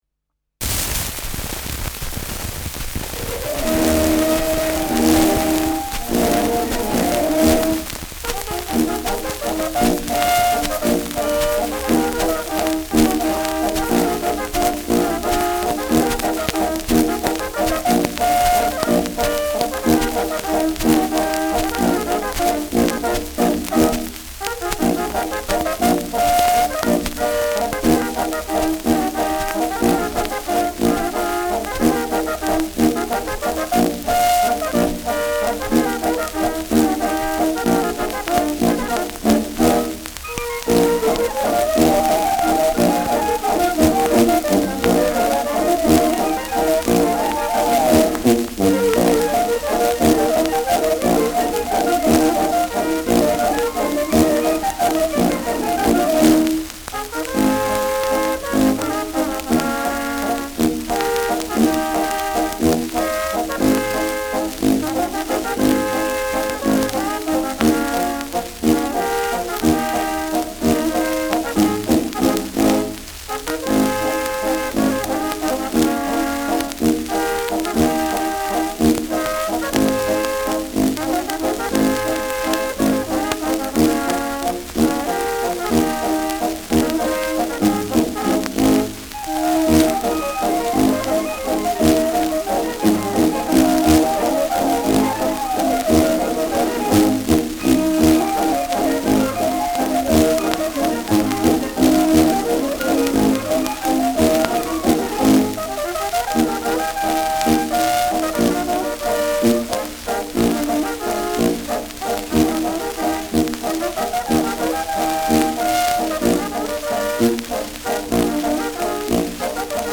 Schellackplatte
starkes Rauschen : präsentes Nadelgeräusch : gelegentliches „Schnarren“ : gelegentliches Knacken : abgespielt : leiert : Knacken bei 2’20’’
Dachauer Bauernkapelle (Interpretation)